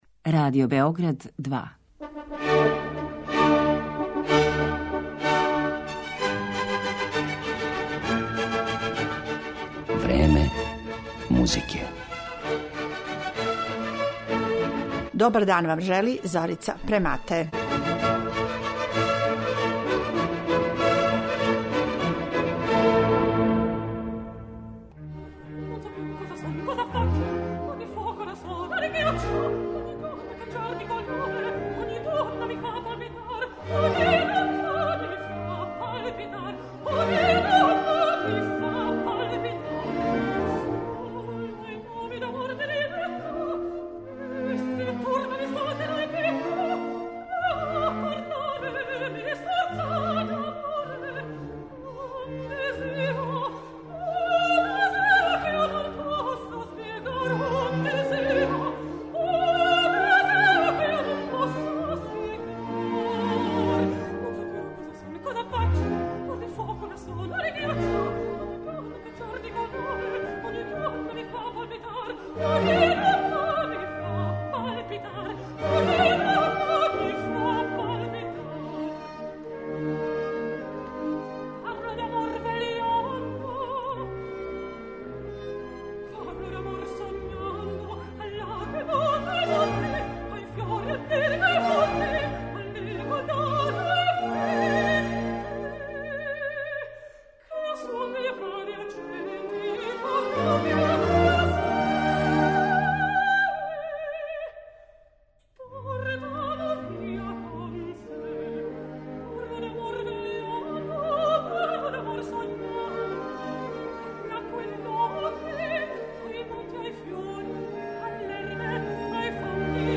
У емисији ћете слушати како примадоне тумаче улоге мушкараца у операма Моцарта, Росинија, Белинија, Вердија, Маснеа и Римског-Корсакова, а на крају ћете чути и један обрнути пример, да мушки глас, бас, пева женску улогу.